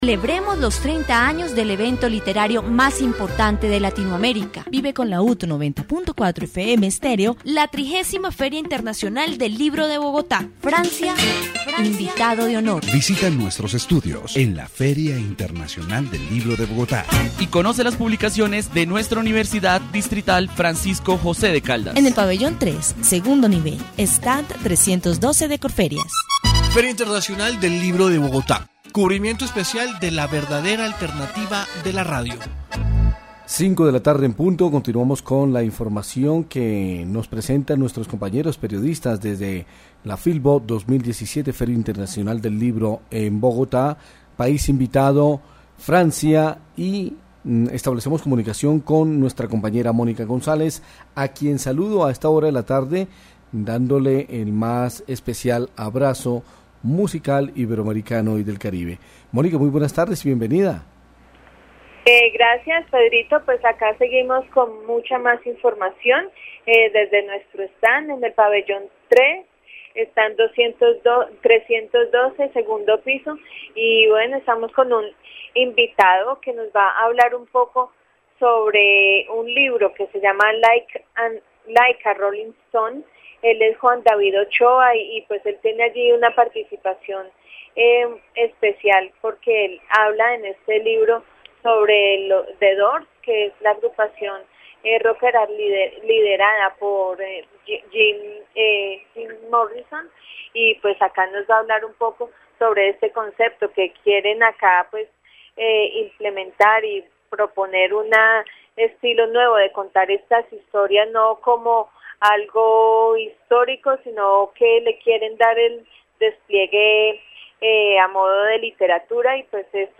dc.subject.lembProgramas de radio
Radio report